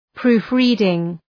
Προφορά
{‘pru:f,ri:dıŋ}